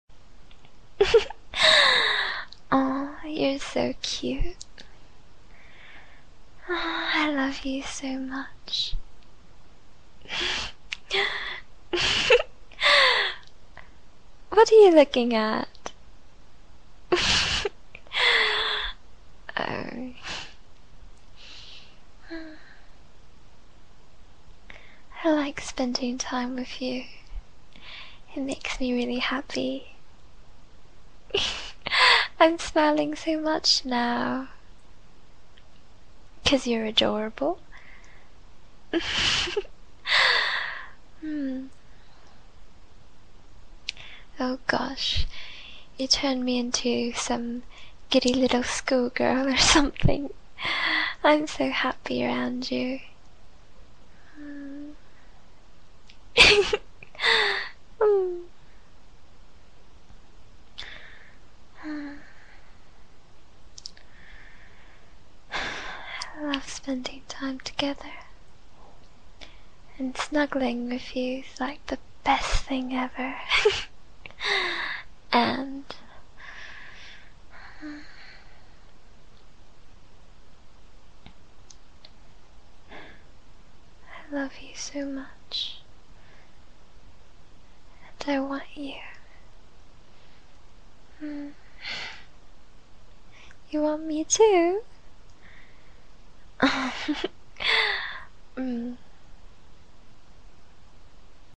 British